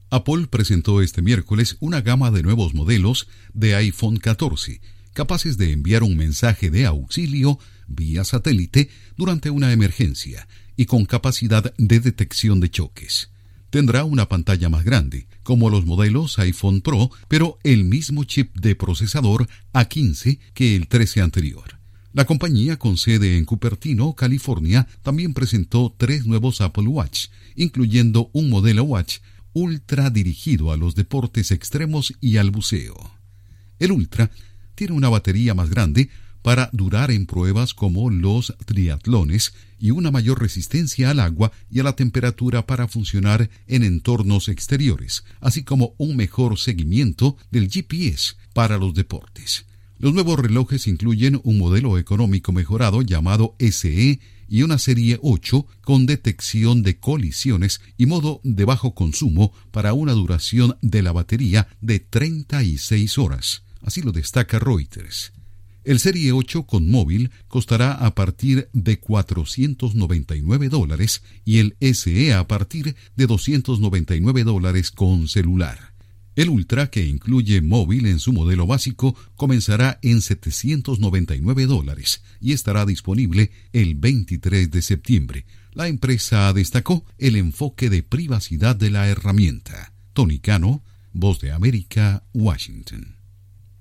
Apple presenta el iPhone 14 con mensajes de emergencia por satélite y el Ultra Watch. Informa desde la Voz de América en Washington